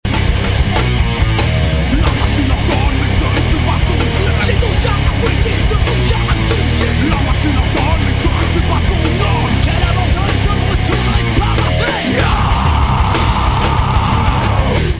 rap métal